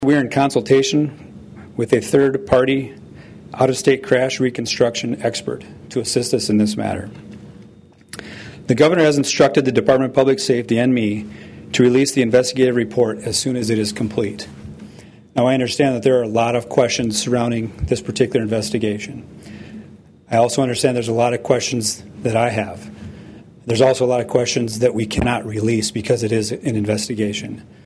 Department of Public Safety Secretary Craig Price answered questions at a news conference and says multiple agencies are involved.